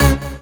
Synths
ED Synths 04.wav